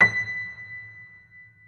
piano-sounds-dev
Vintage_Upright
b5.mp3